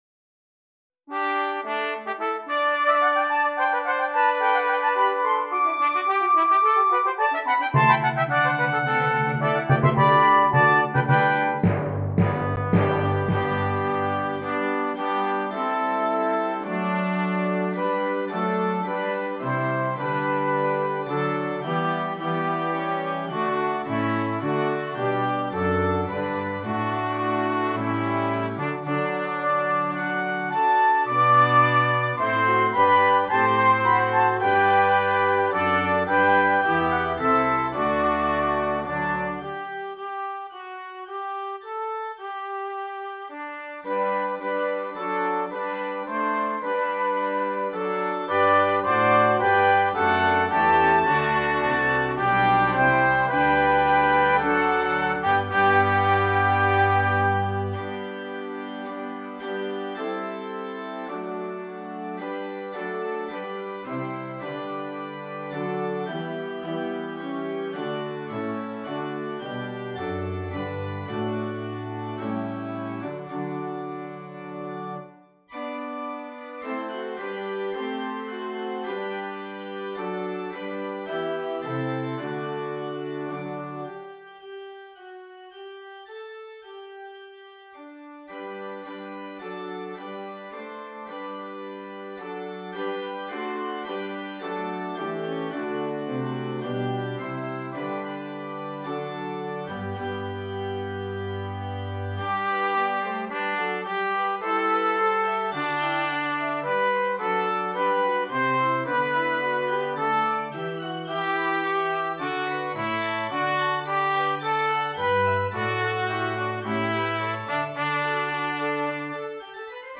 Brass Quintet, Organ and Optional Choir
Traditional